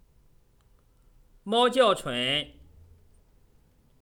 28猫叫春